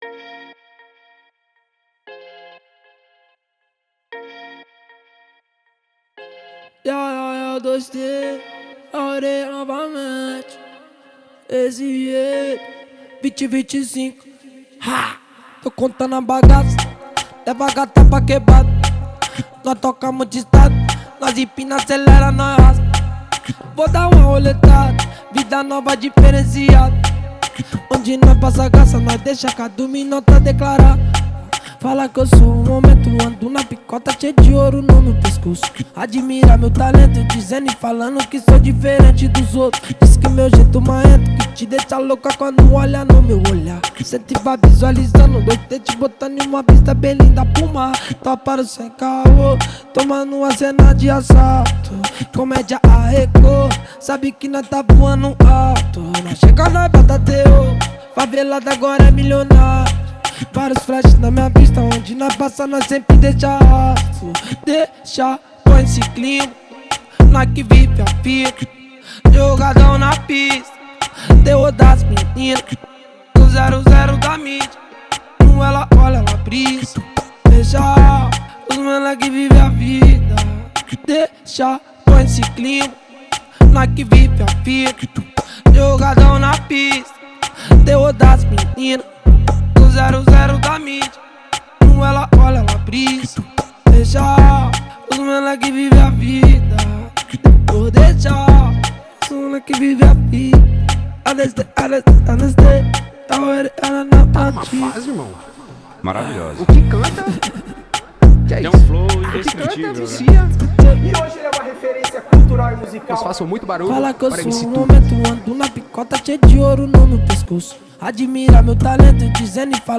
Gênero: Funk